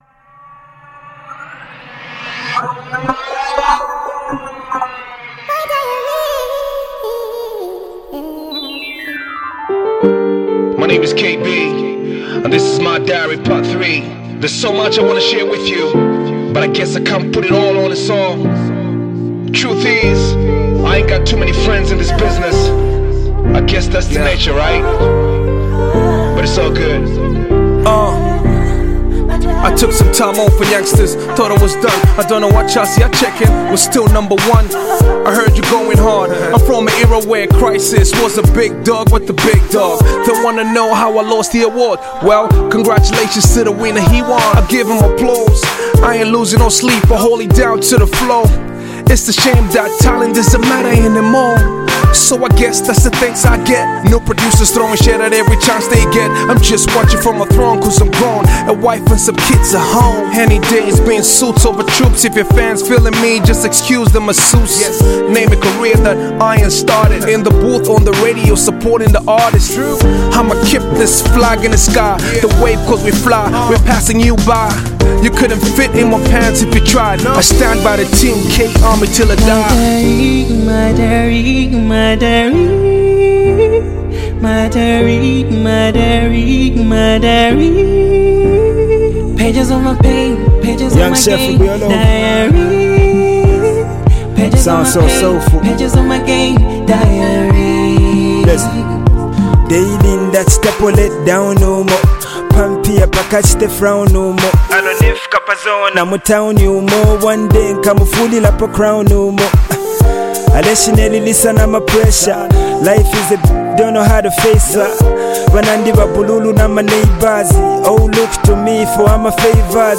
rap verse
R&B singer